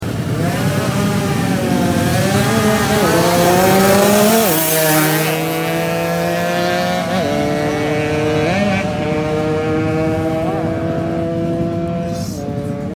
Test drag boring rx king sound effects free download